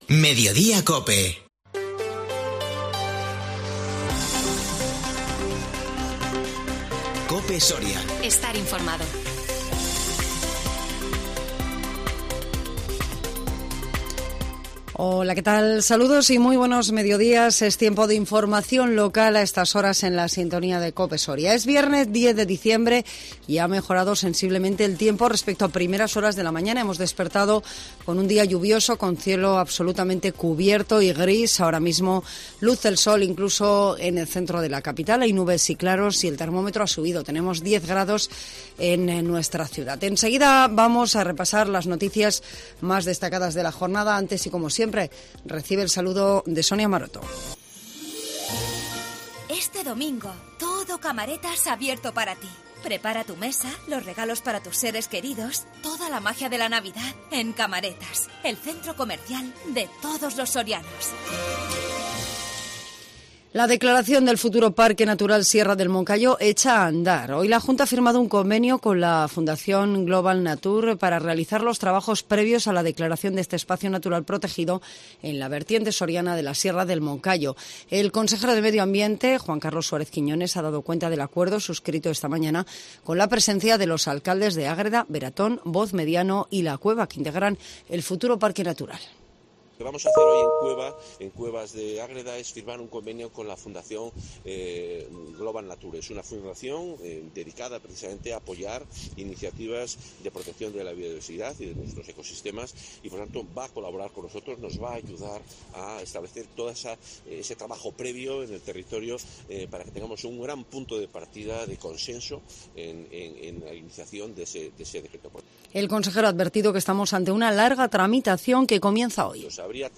INFORMATIVO MEDIODÍA 10 DICIEMBRE 2021